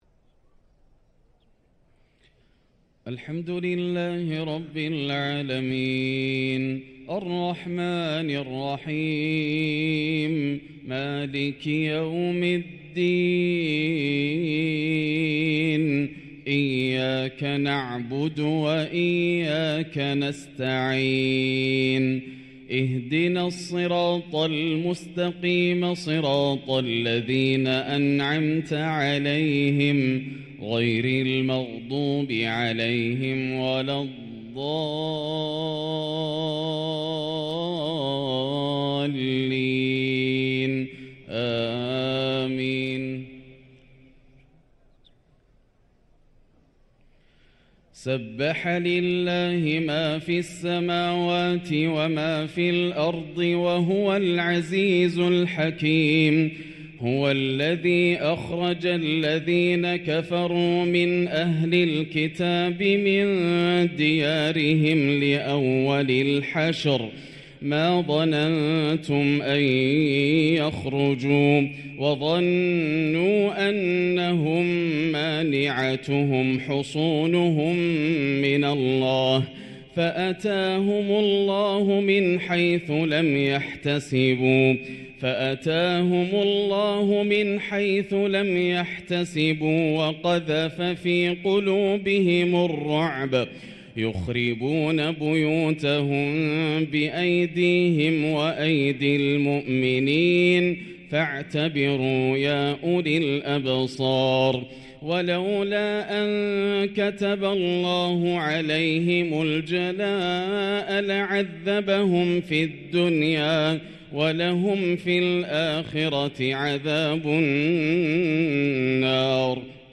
صلاة الفجر للقارئ ياسر الدوسري 6 صفر 1445 هـ